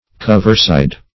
Search Result for " coverside" : The Collaborative International Dictionary of English v.0.48: Coverside \Cov"er*side`\, n. A region of country having covers; a hunting country.